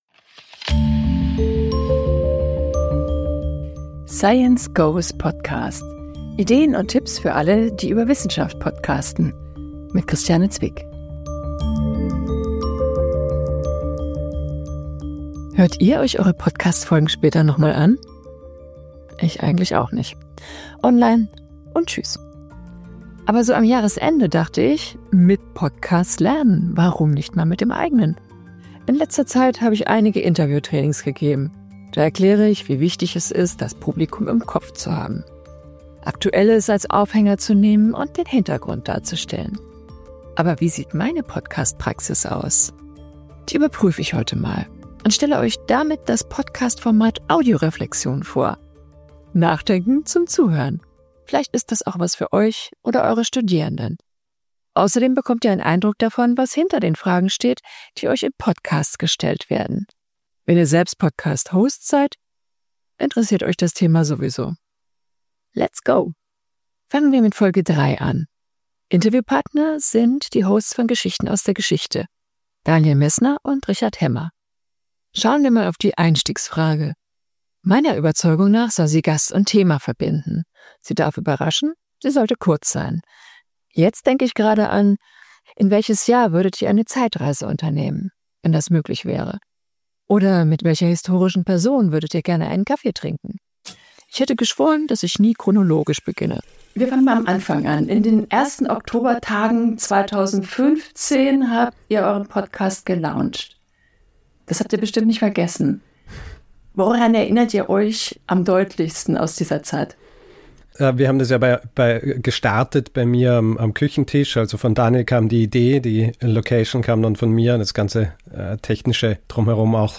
Episodenformat: Audioreflexion Gestaltungsmittel in dieser Episode: Interviewausschnitte zwei Erzählebenen Effekttöne